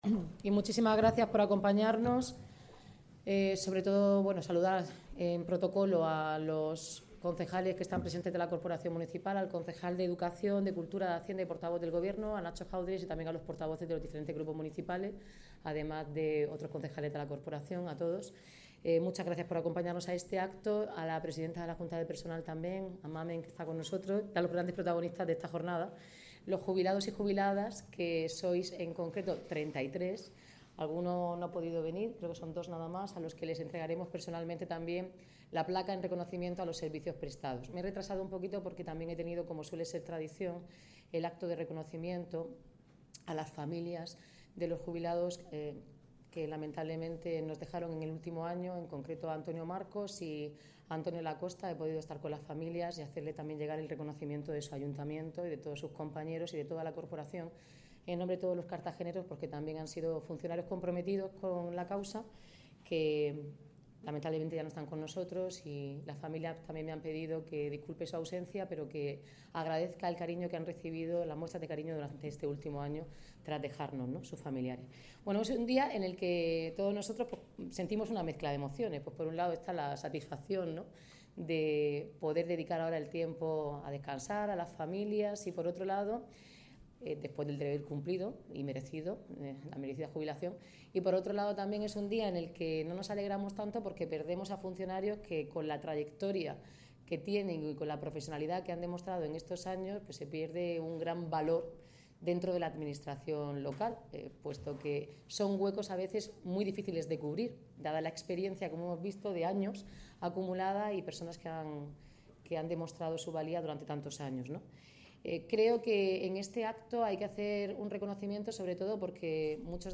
Declaraciones de la alcaldesa Noelia Arroyo (MP3 - 2,89 MB)Enlace a audio Galería de Imágenes.